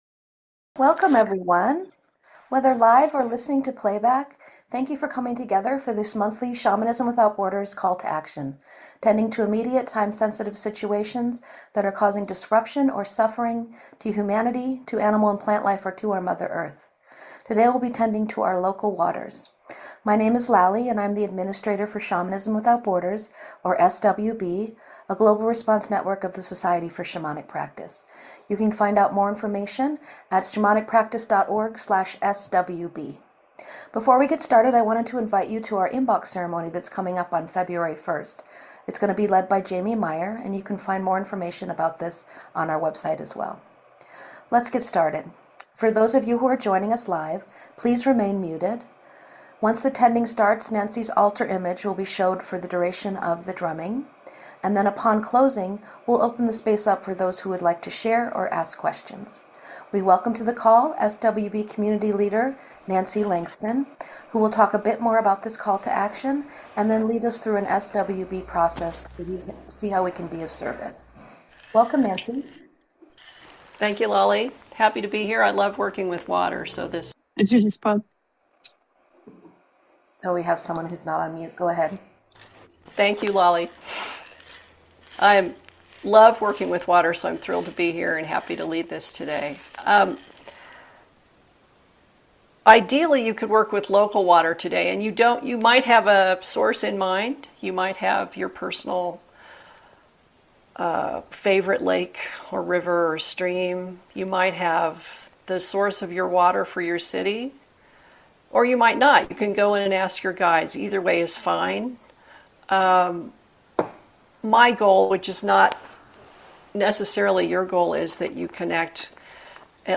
You are welcome to rattle or drum along during the shamanic journey process.